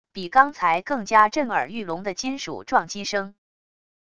比刚才更加震耳欲聋的金属撞击声wav音频